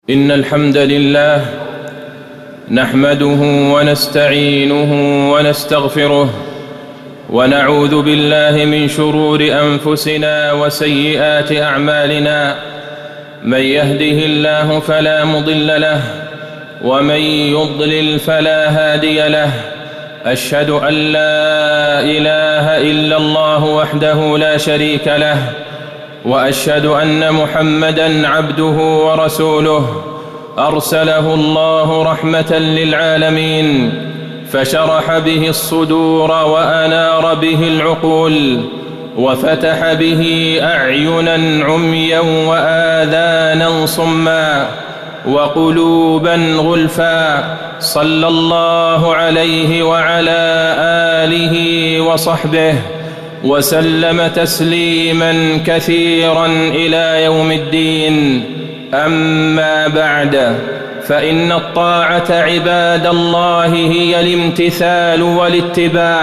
تاريخ النشر ٢١ جمادى الآخرة ١٤٣٩ هـ المكان: المسجد النبوي الشيخ: فضيلة الشيخ د. عبدالله بن عبدالرحمن البعيجان فضيلة الشيخ د. عبدالله بن عبدالرحمن البعيجان خطورة المعاصي والذنوب